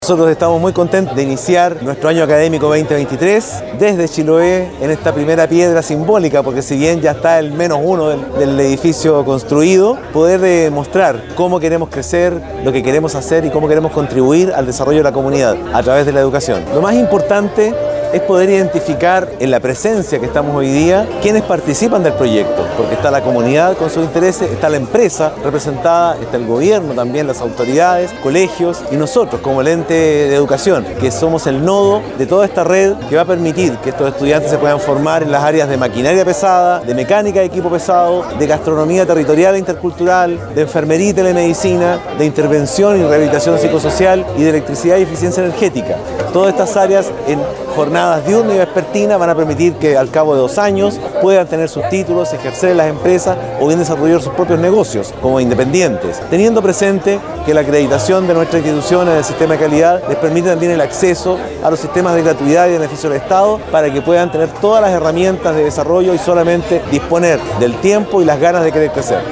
Castro: CEDUC UCN inaugura Año Académico 2023 con ceremonia de Primera Piedra en nueva sede Chiloé.